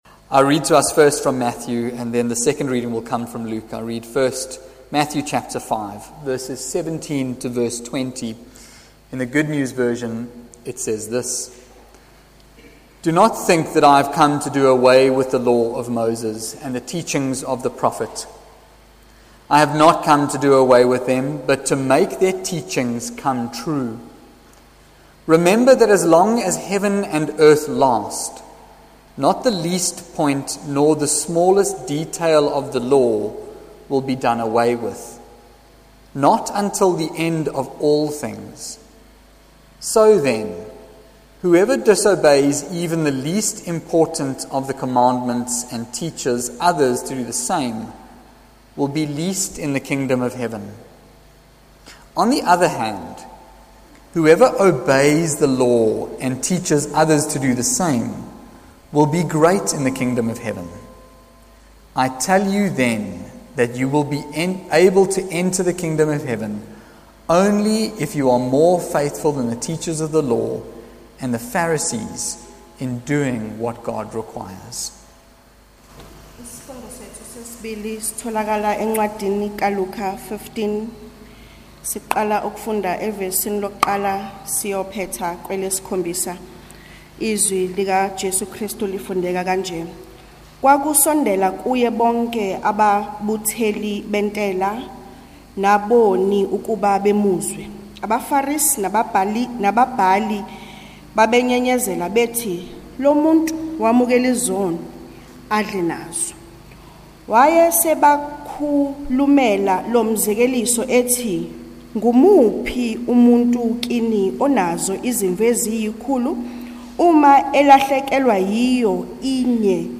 Trinity Methodist Church Sermons